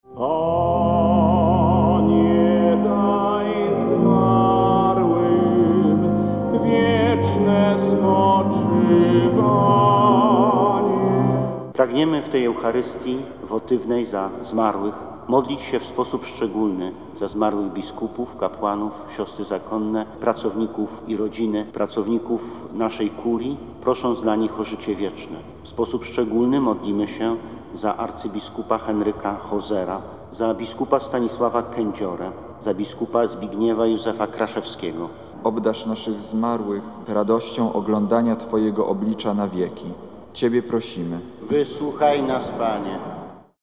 W praskiej katedrze przy ul. Floriańskiej 3 odprawiono Mszę świętą w intencji zmarłych księży biskupów i pracowników instytucji diecezjalnych.
Eucharystii przewodniczył bp Jacek Grzybowski.